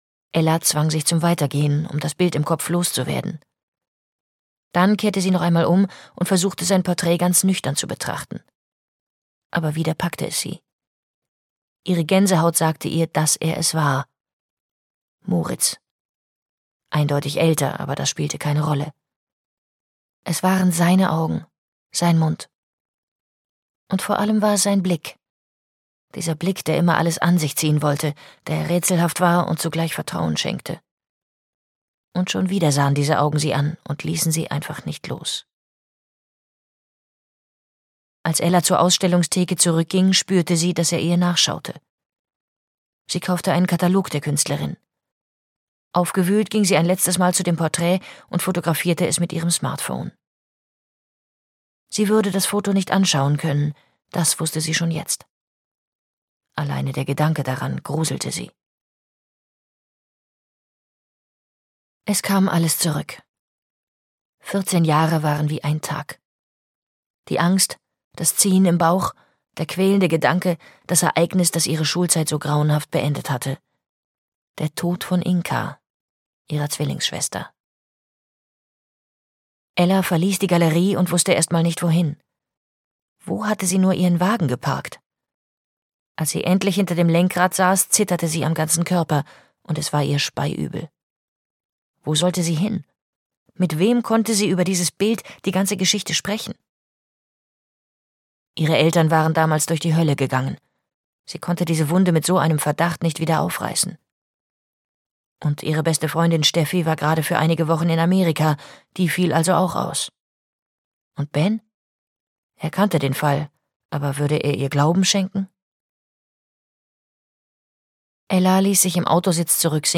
Liebesnöter - Gaby Hauptmann - Hörbuch